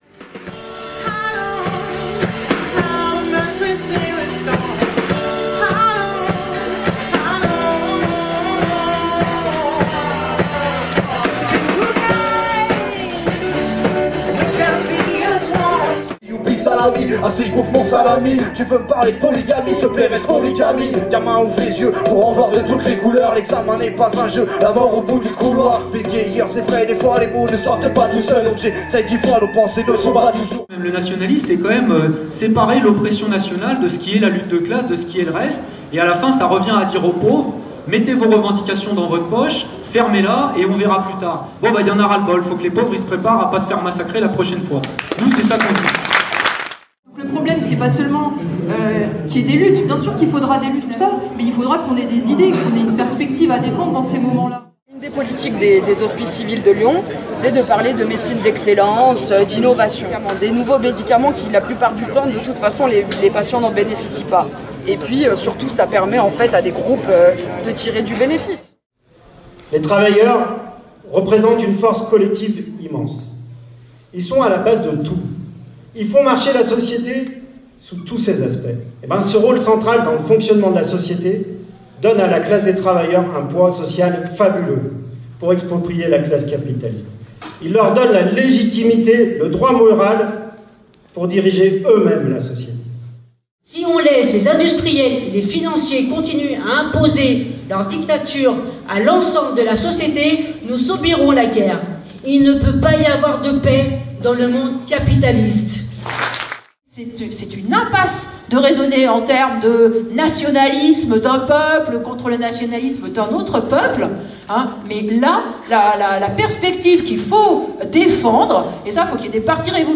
Une vidéo souvenir de la fête 2024 à Saint-Priest, alors que la fête 2025 aura lieu le week-end du 27-28 septembre, à Saint-Fons.